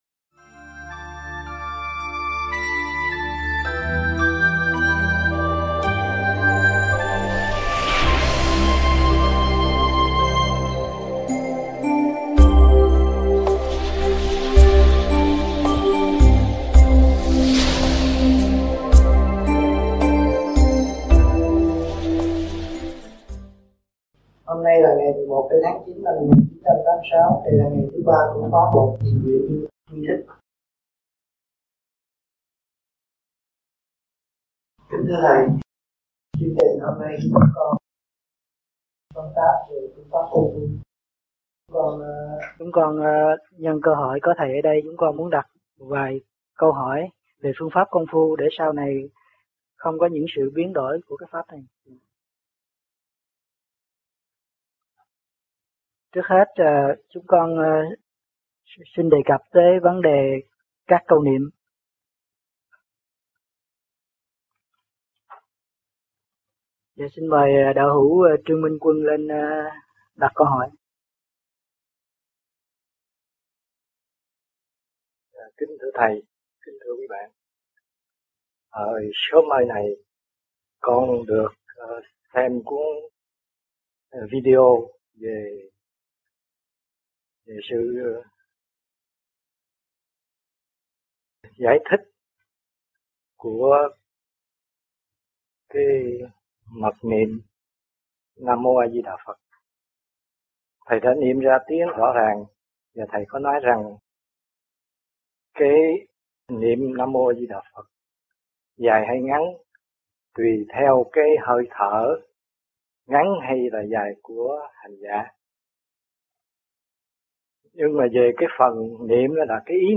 THUYẾT GIẢNG